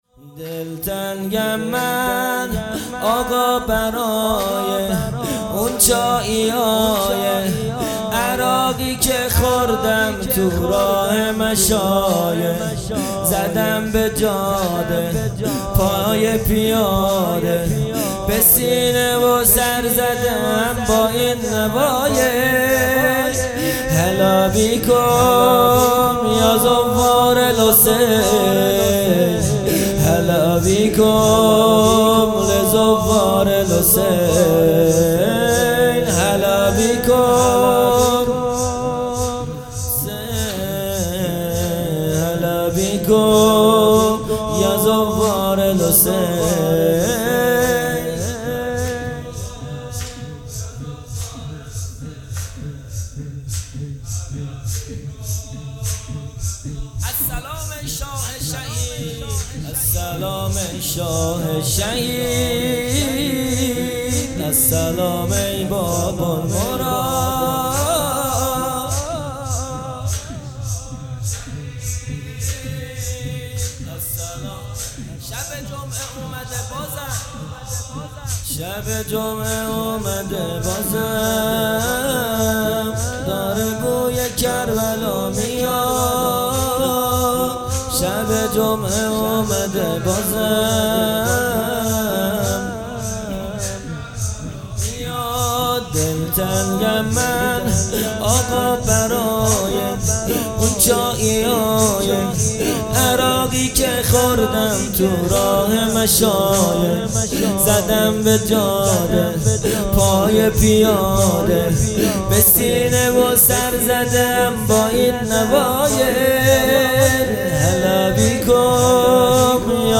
جلسه‌ هفتگی | پنج شنبه ۸ مهر ماه ۱۴۰۰